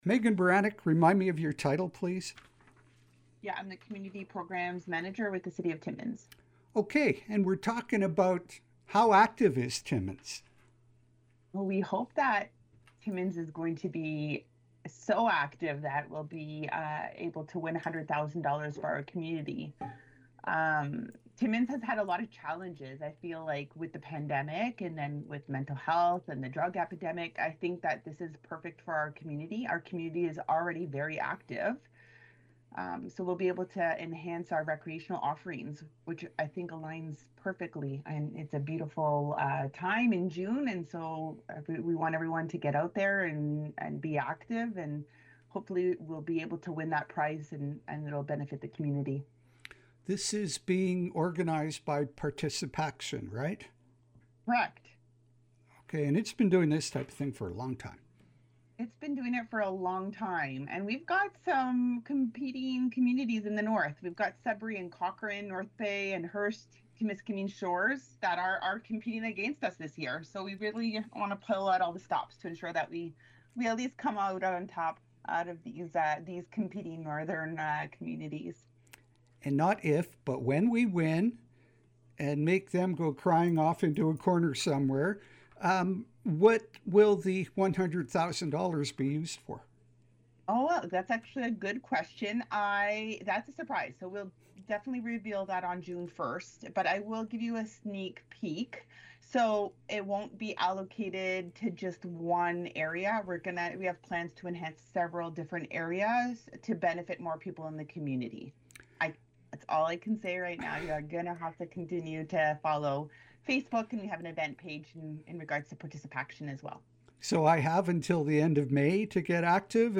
Here is audio of our interview: